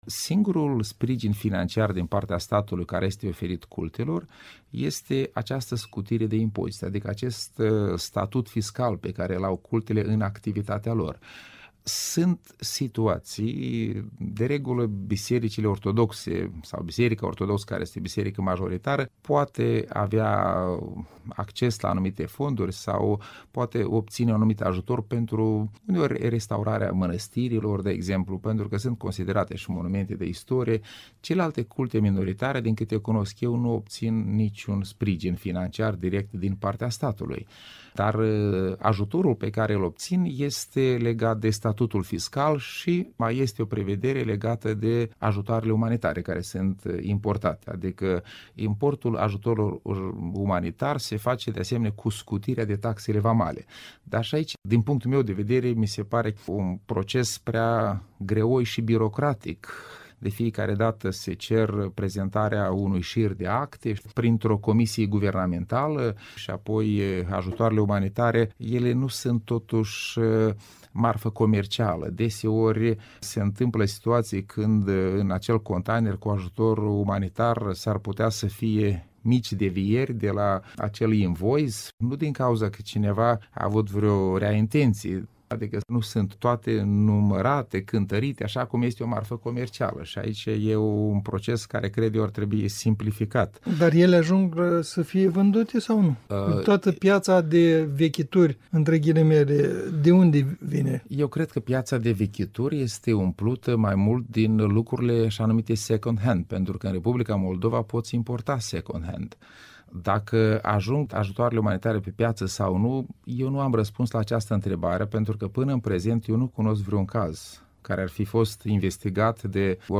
Interviu cu Valeriu Ghileţchi